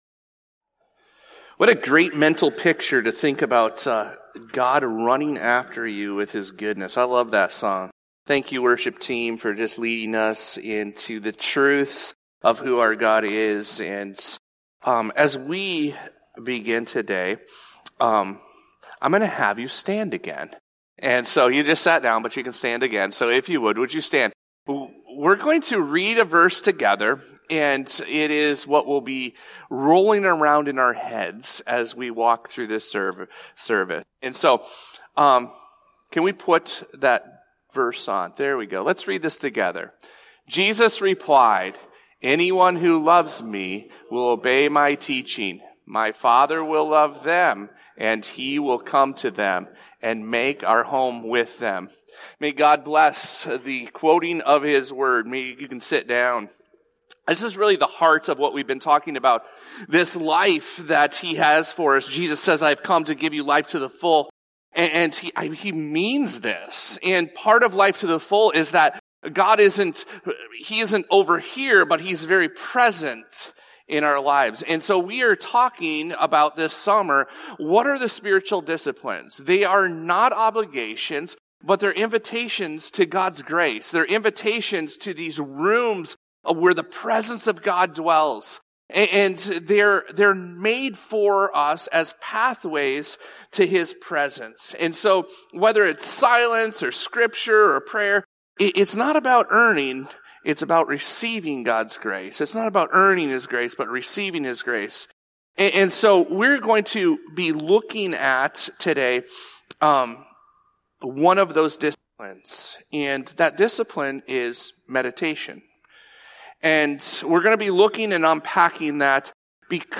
This podcast episode is a Sunday message from Evangel Community Church, Houghton, Michigan, June 15, 2025.